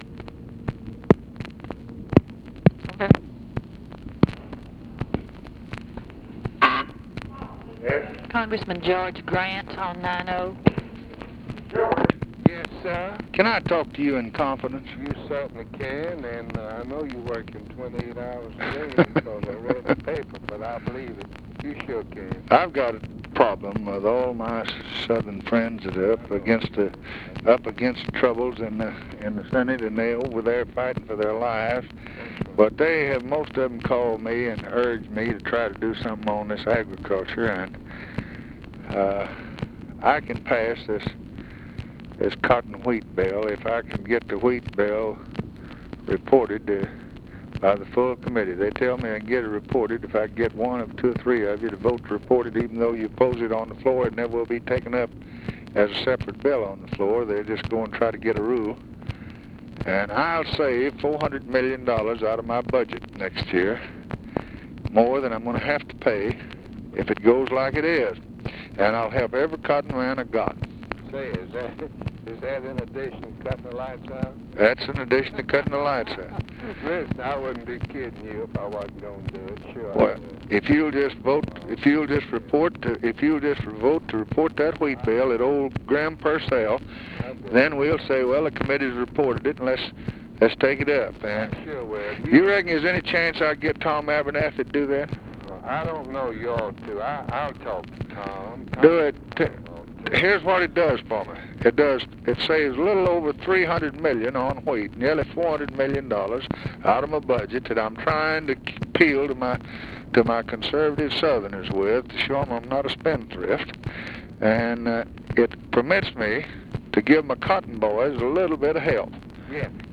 Conversation with GEORGE GRANT, March 9, 1964
Secret White House Tapes